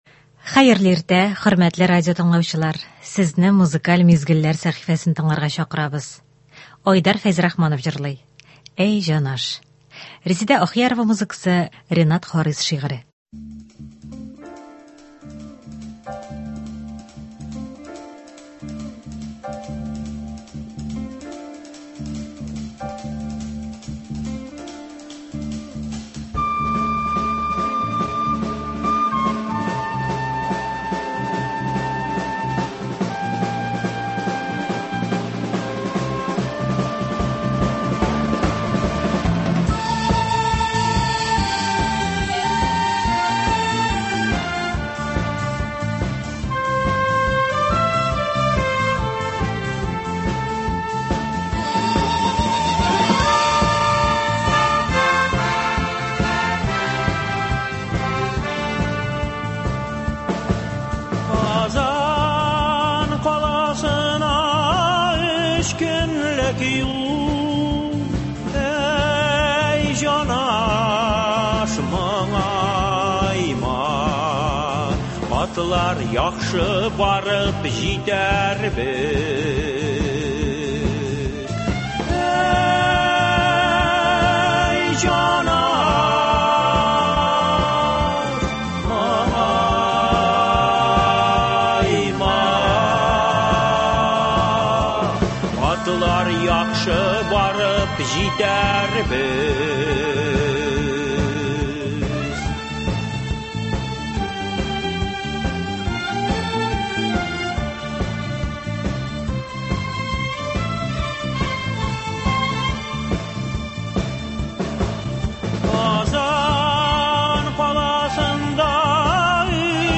Бу иртәне дә күңелне күтәрә торган җырлар белән каршылыйк!